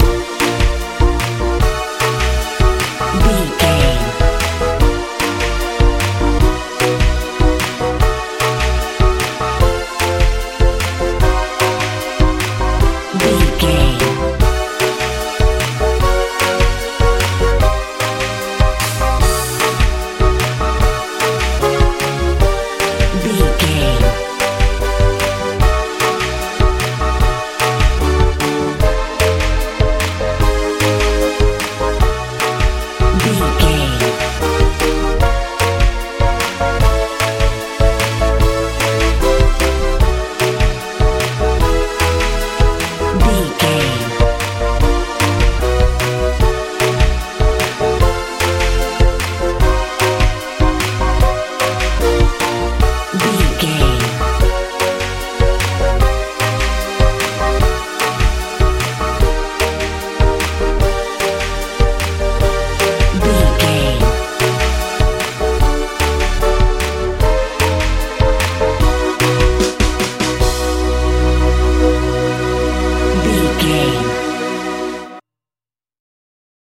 pep squad pop
Ionian/Major
B♭
energetic
motivational
synthesiser
piano
bass guitar
drums
80s
90s
strange